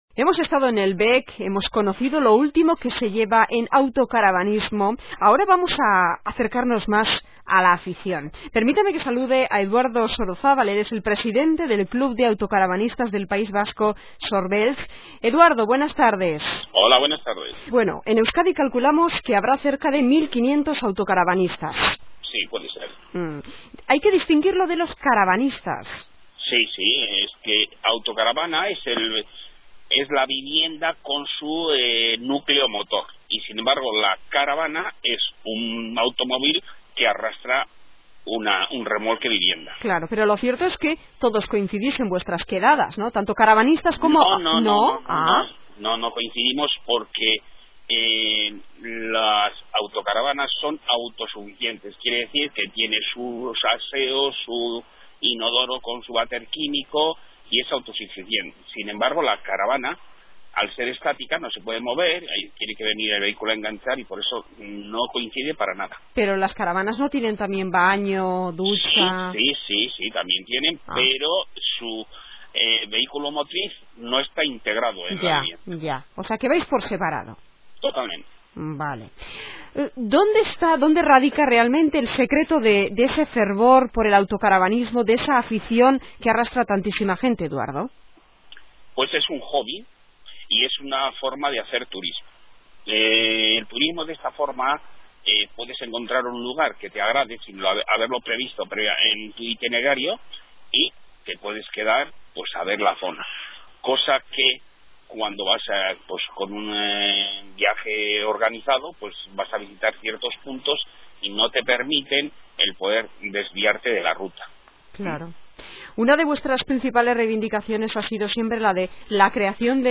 Una nueva entrevista en la cadena SER - Sorbeltz Elkartea
Con un clic en la imagen escucharas una nueva entrevista de divulgación del club en la radio